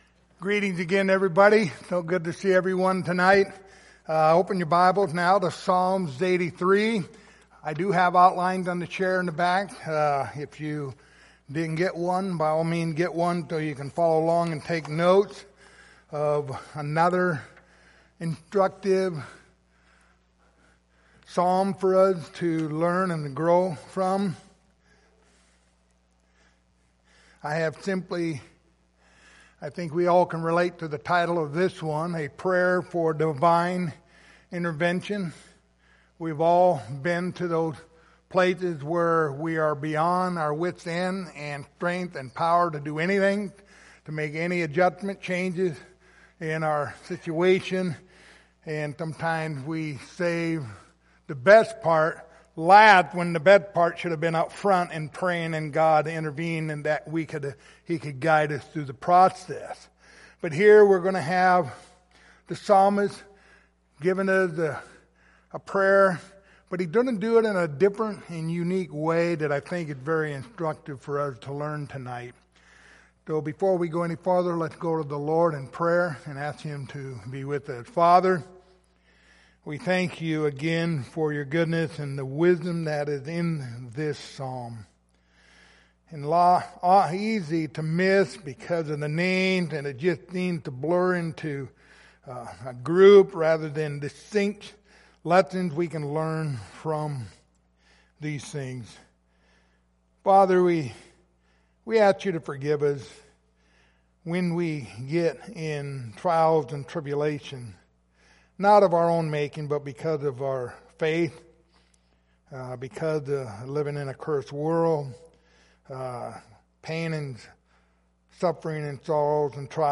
Passage: Psalms 83:1-18 Service Type: Sunday Evening